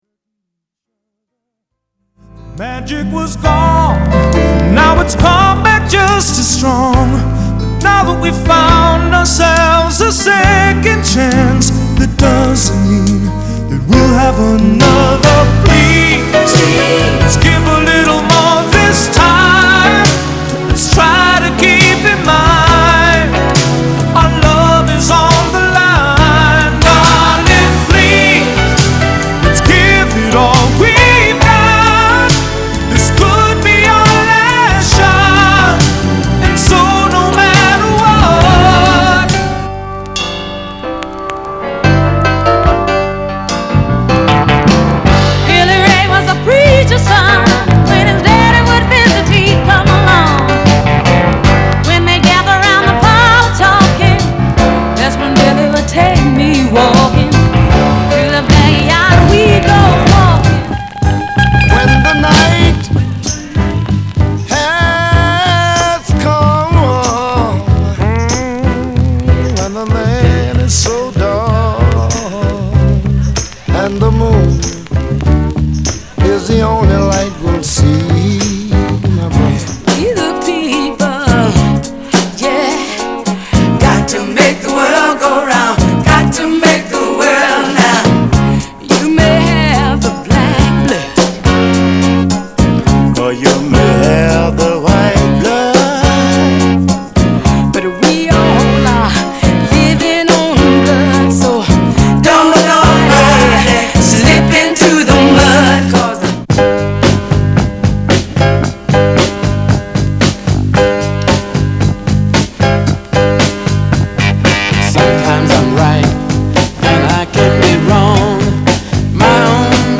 当前位置 > 首页 >音乐 >唱片 >R＆B，灵魂
/盤質/両面やや傷あり/US PRESS